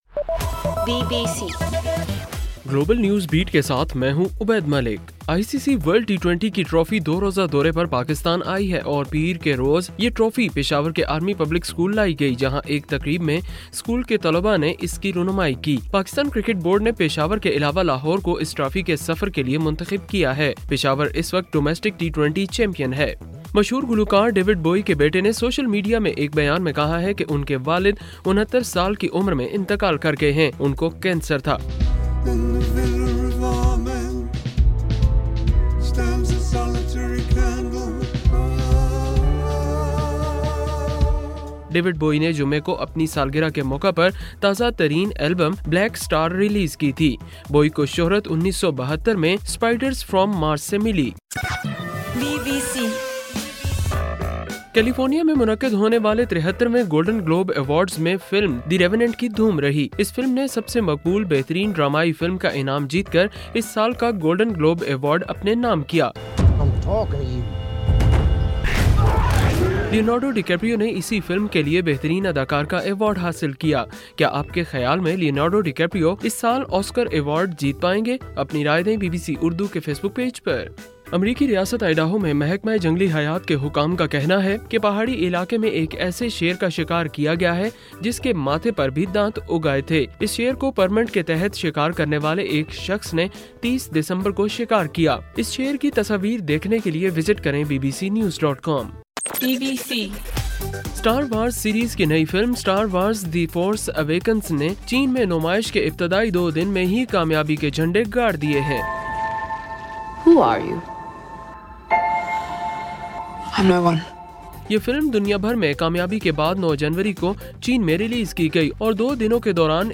جنوری 11: رات 9 بجے کا گلوبل نیوز بیٹ بُلیٹن